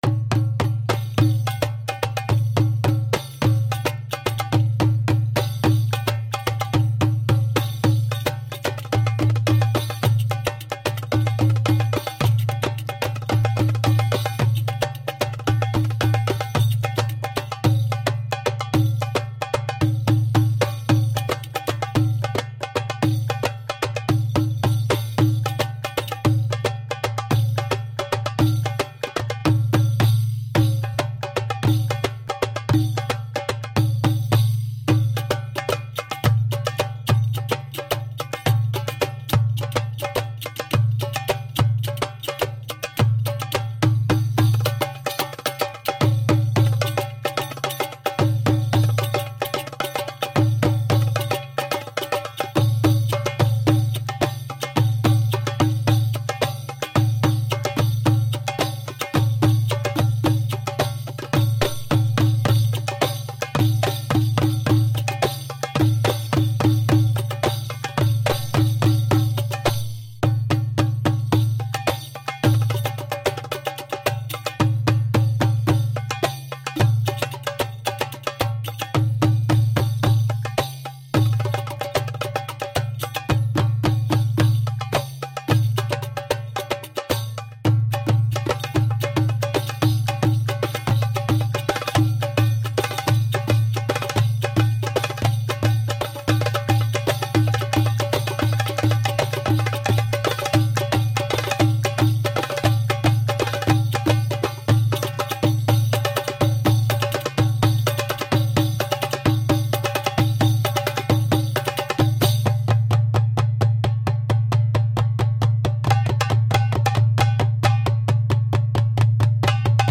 Sultry and percussive mid-east fusion.